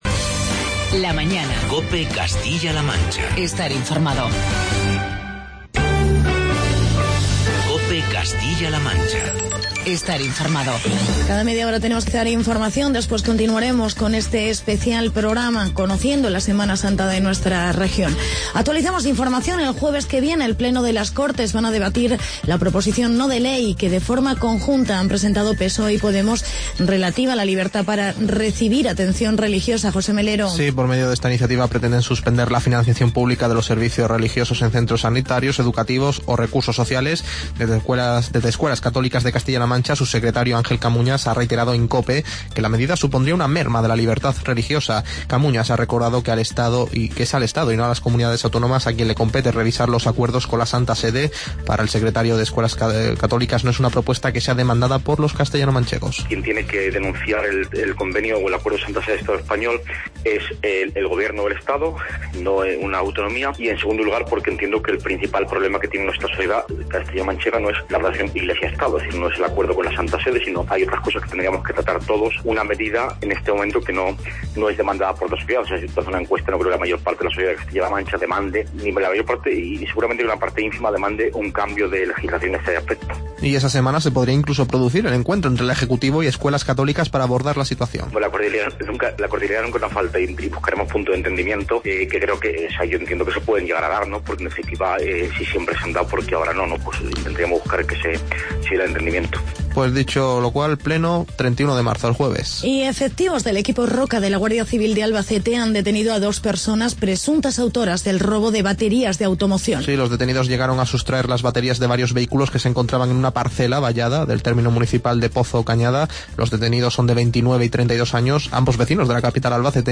Programa especial Semana Santa CLM. Reportajes de Guadalajara, Sigüenza y Toledo.